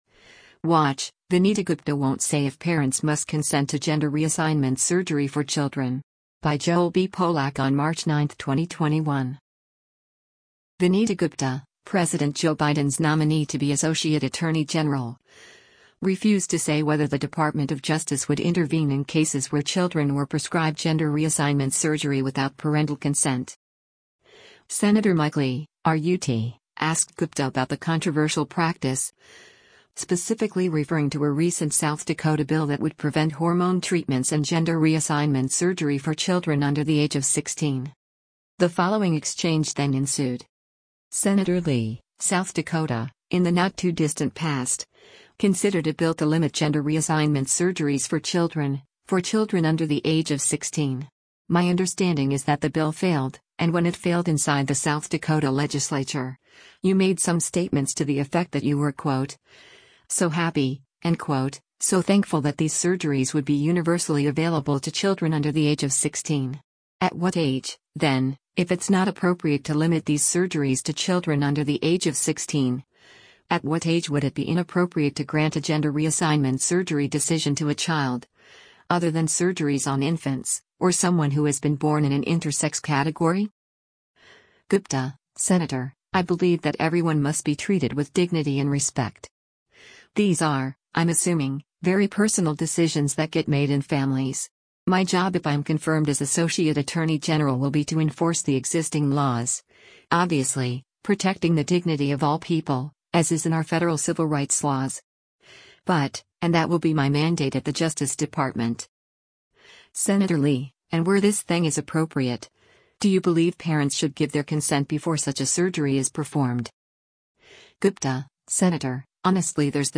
Video Source: Senate Judiciary Committee
Sen. Mike Lee (R-UT) asked Gupta about the controversial practice, specifically referring to a recent South Dakota bill that would prevent hormone treatments and gender reassignment surgery for children under the age of 16.